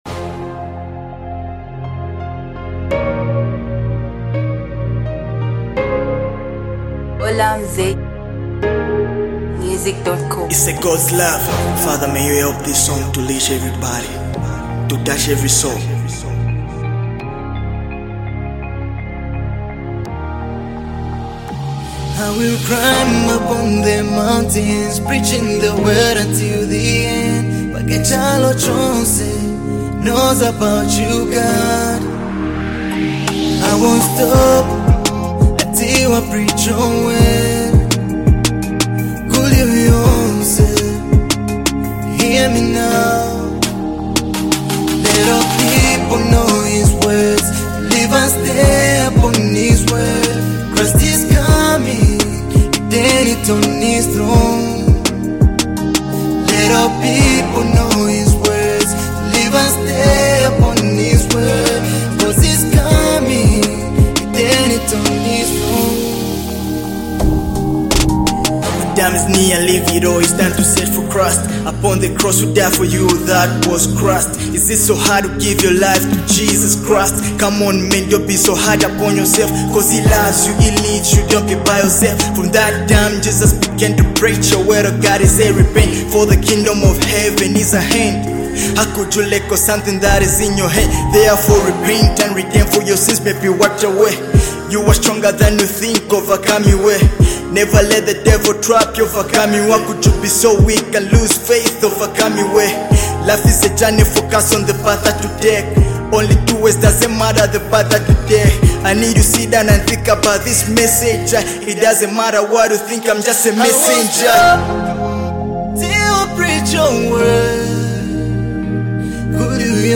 gospel hip hop song